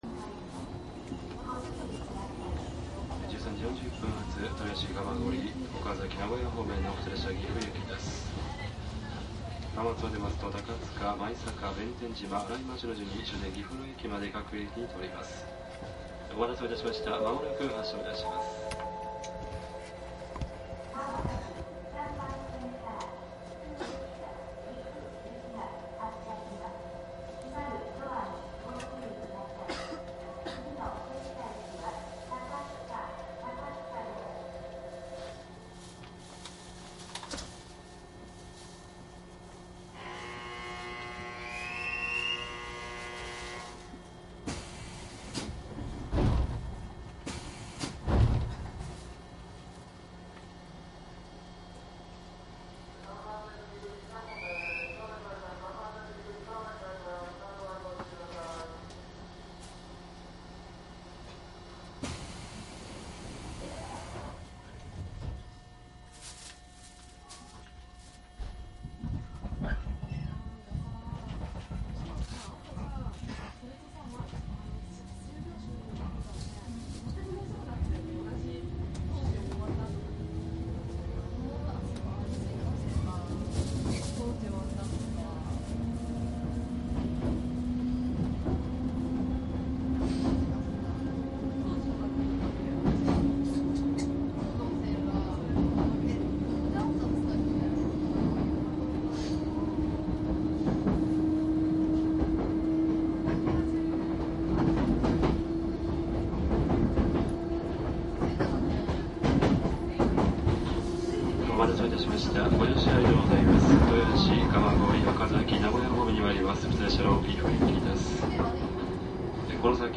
東海 311系・117系走行音CD
浜松～豊橋で311系と117系を録音しています。どちらも18切符期間中で、なので乗客が全区間ガッチリ乗ってますので、客の会話が時々聞こえます。
【普通】浜松→豊橋
サンプル音声   クモハ311-１４.mp3
いずれもマイクECM959です。DATかMDの通常SPモードで録音。
実際に乗客が居る車内で録音しています。貸切ではありませんので乗客の会話やが全くないわけではありません。